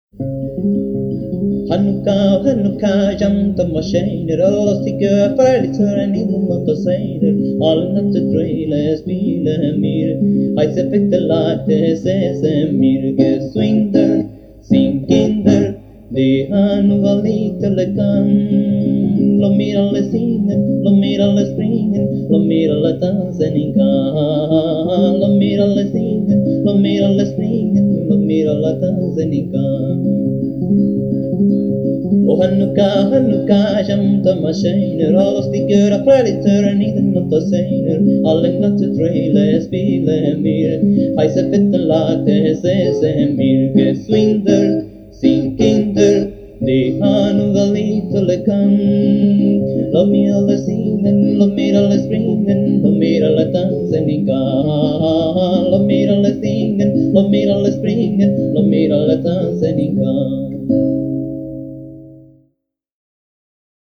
Guitarra y voz